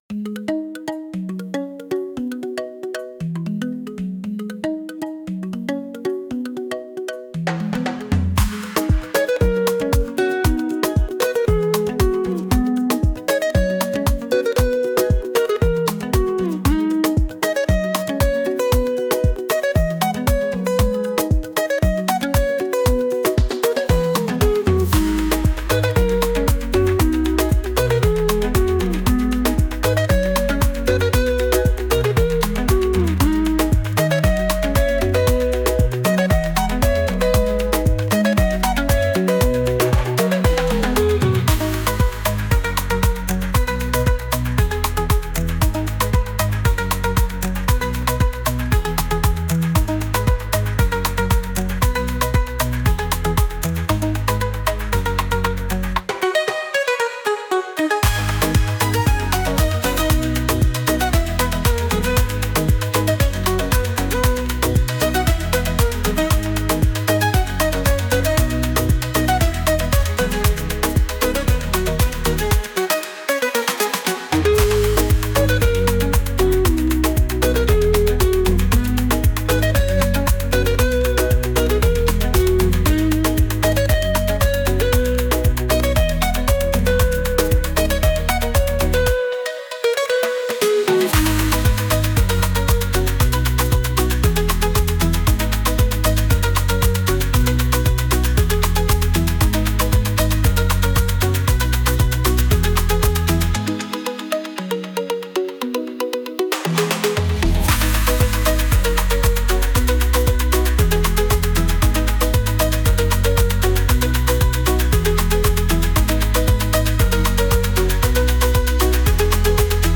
Latin ensemble with congas, bongos, and timbales